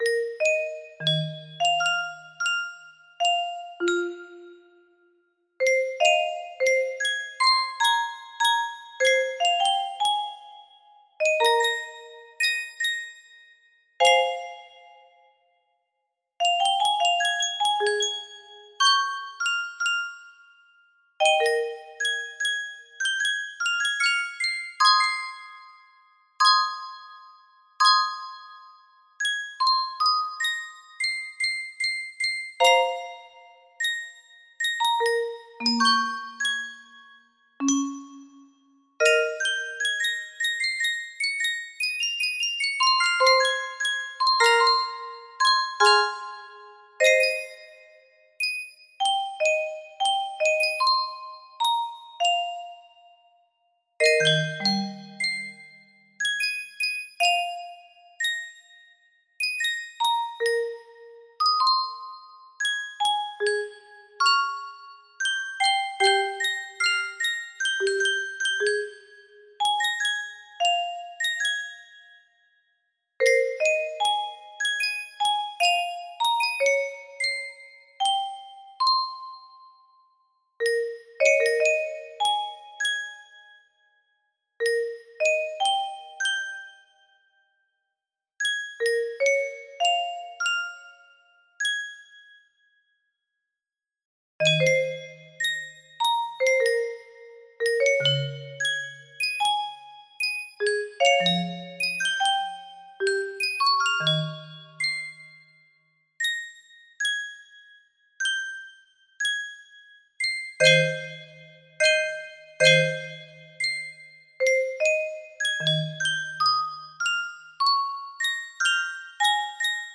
Full range 60
No reds, Very raw melody.